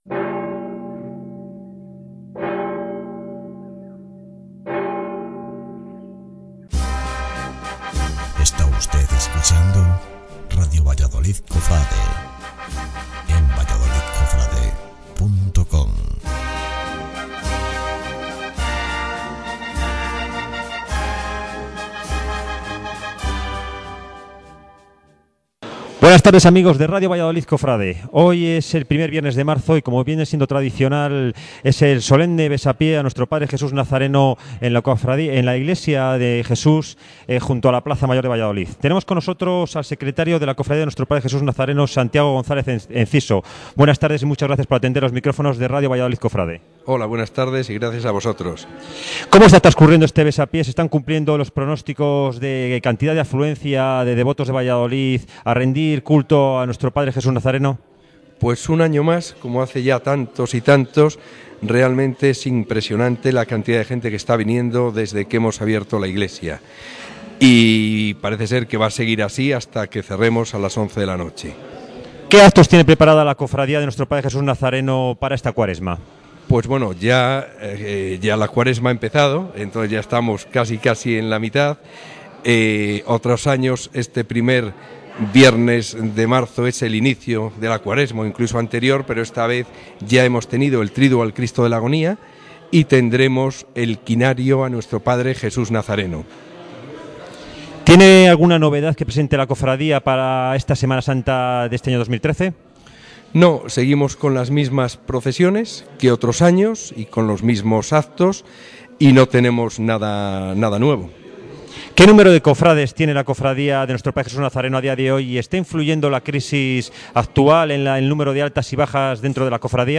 Entrevista
entrevistanazareno.mp3